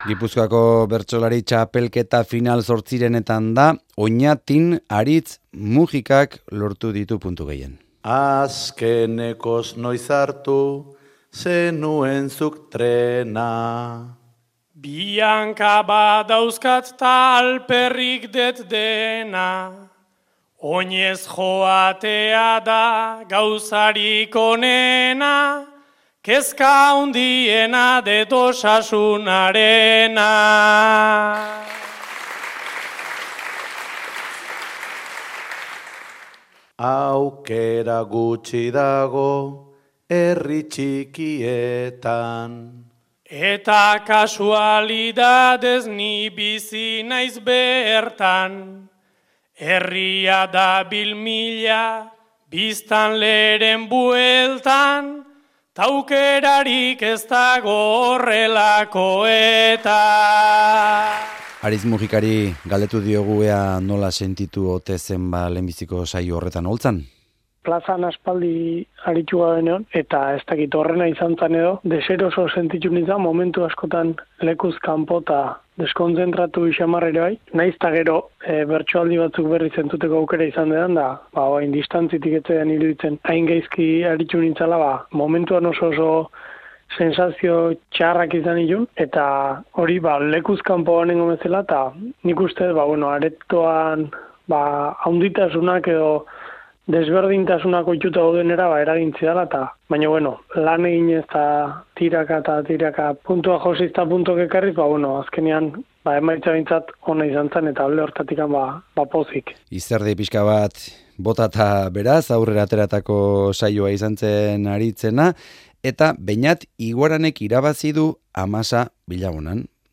hizketan eta bertsotan